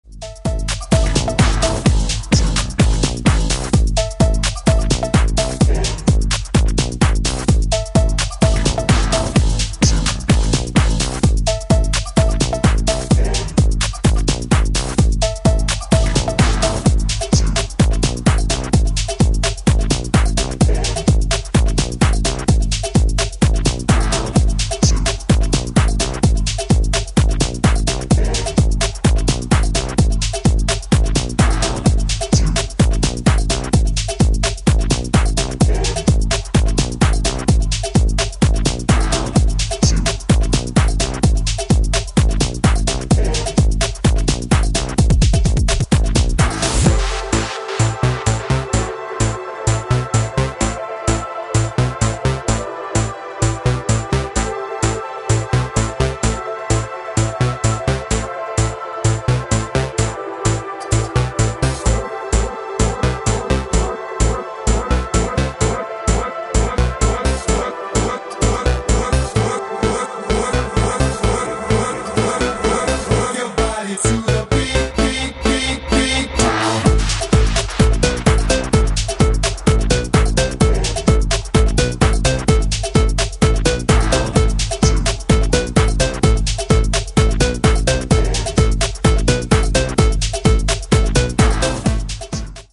アップ・ハウスでフロア重視の全4バージョン
ジャンル(スタイル) HOUSE / DISCO HOUSE